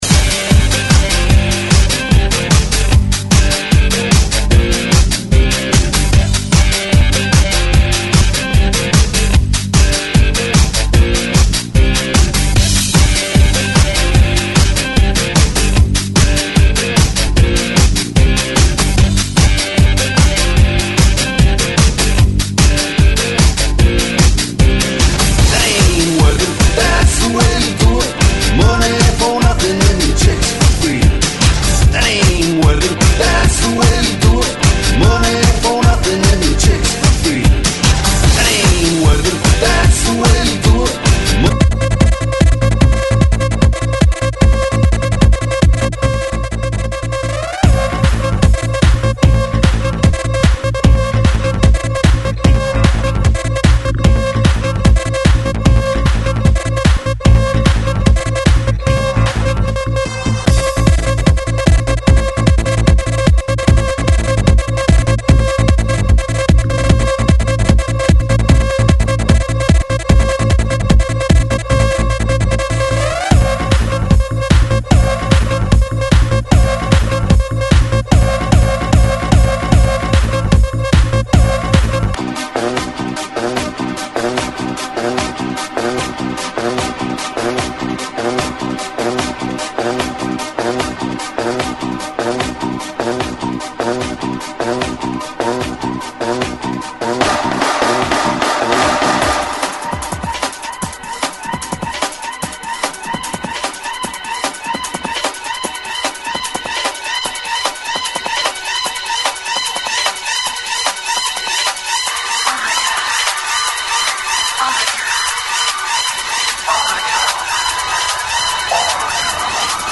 GENERO: ELECTRONICA